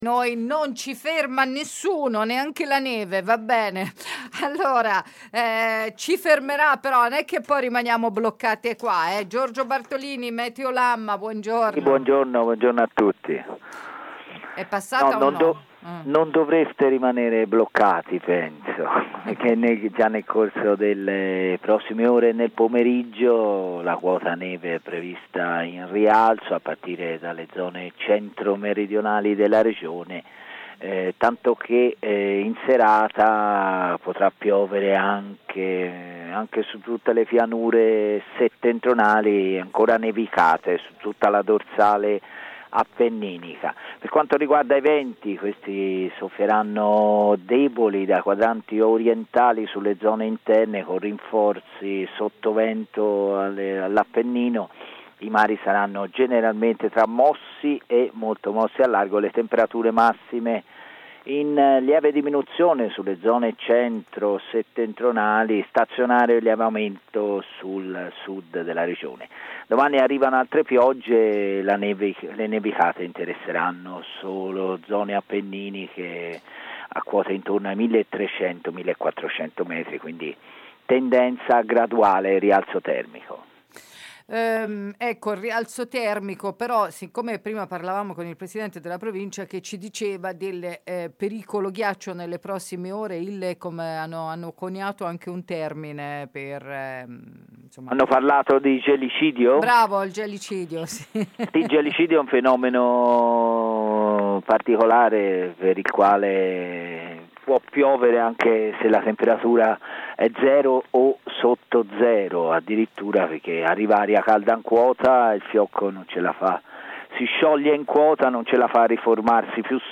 Neve sotto controllo sul territorio di Siena e provincia. Codice arancione attivo fino alle 18 *intervista*